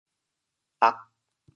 “垩”字用潮州话怎么说？
垩 部首拼音 部首 土 总笔划 9 部外笔划 6 普通话 è 潮州发音 潮州 og4 文 潮阳 ag4 澄海 ag4 揭阳 ag4 饶平 ag4 汕头 ag4 中文解释 垩 <名> (形声。